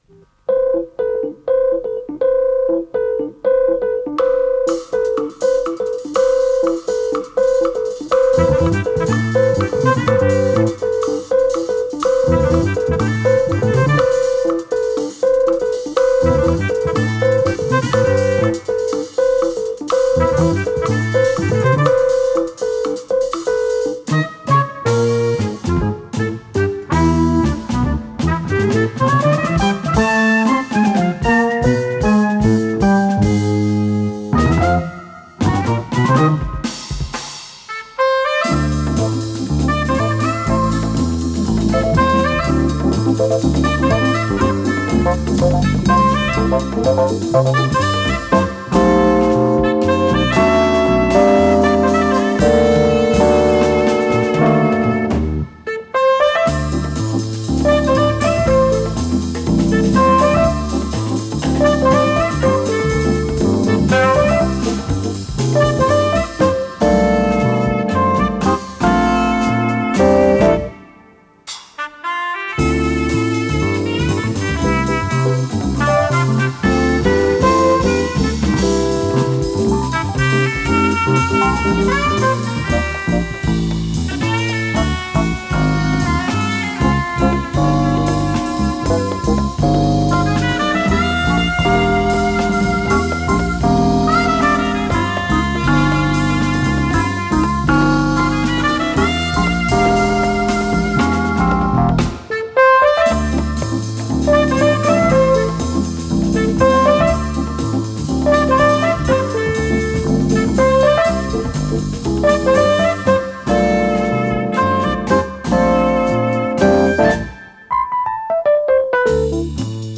с профессиональной магнитной ленты
ПодзаголовокПьеса для джаз-ансамбля, ля минор
клавишные инструменты
саксофон-сопрано
электро-бас
ударные
ВариантДубль моно